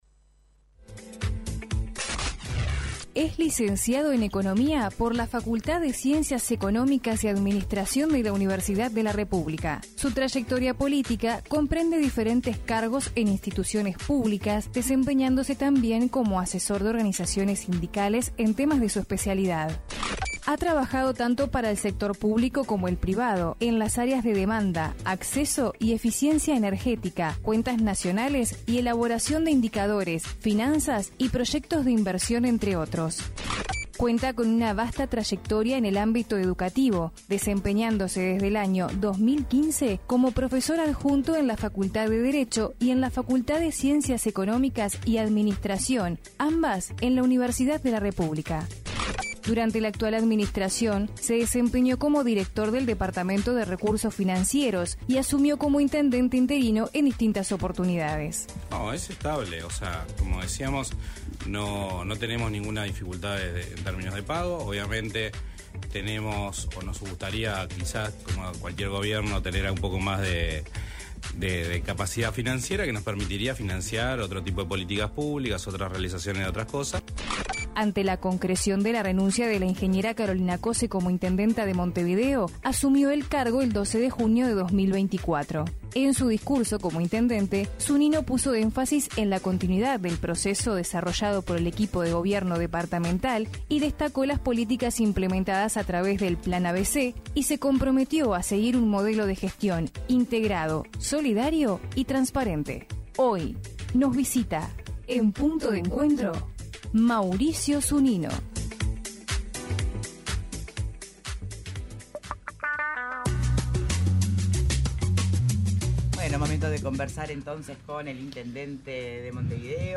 El intendente de Montevideo, Mauricio Zunino se refirió en entrevista con Punto de Encuentro la situación vinculada al preconflicto con los trabajadores de Tv Ciudad.